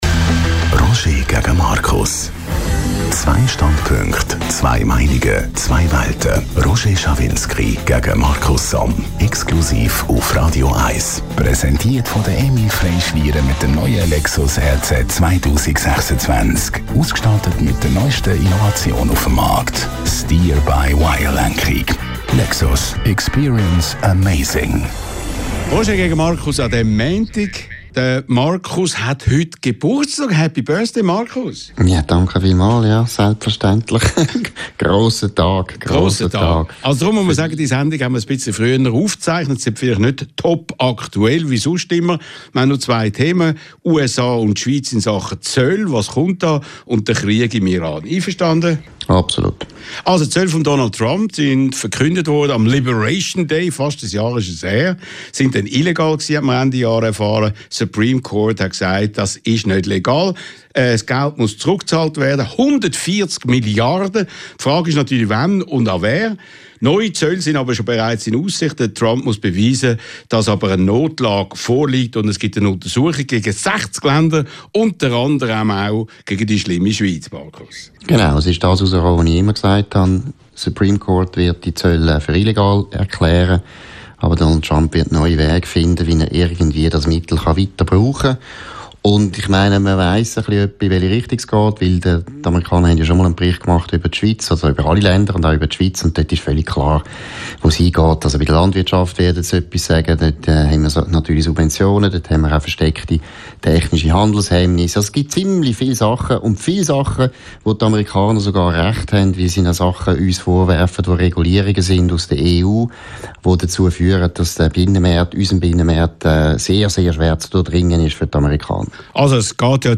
Publizist Markus Somm und Radio 1-Chef Roger Schawinski diskutieren in kontroverser Form über aktuelle Themen der Woche.